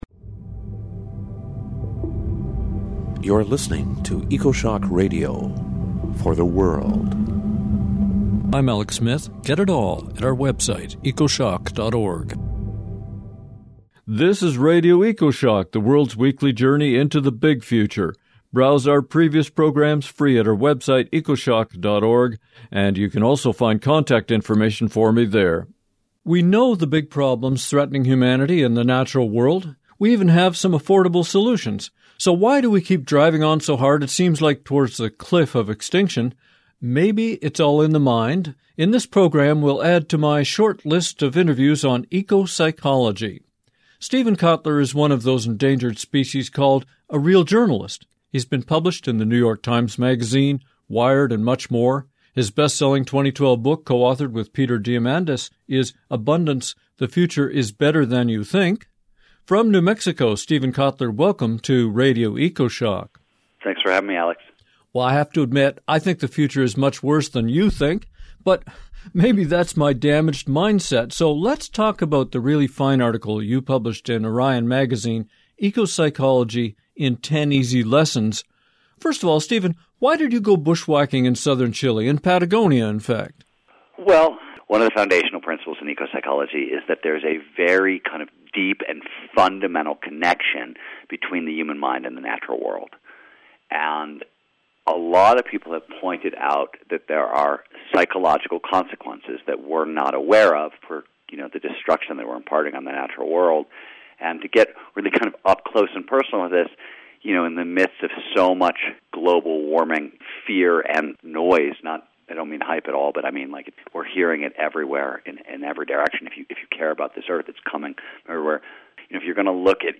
Then well-known journalist Steven Kotler takes us on a tour of ecopsychology in ten easy steps. Is it a diversion for comfortable coffee shops or “the answer”.